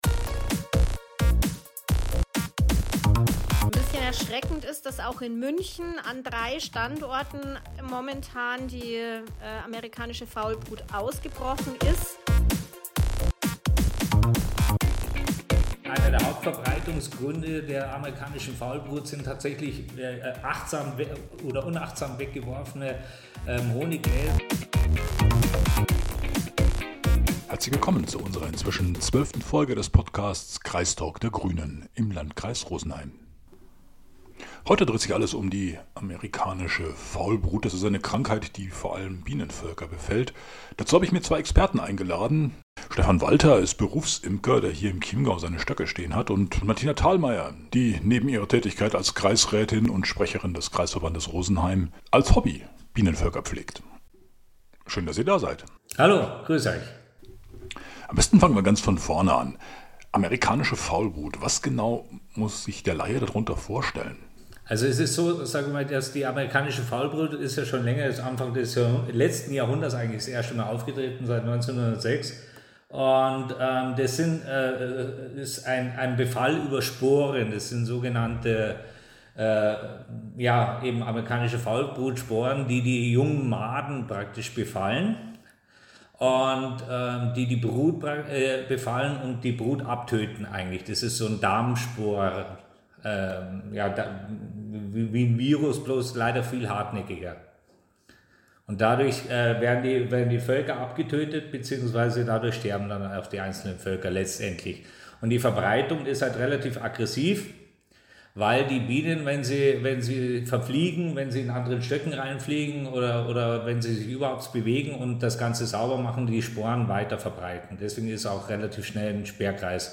Im Lankreis Rosenheim ist die amerikanische Faulbrut ausgebrochen. Wir sprechen mit dem Berufsimker und Kreisrat Stephan Walter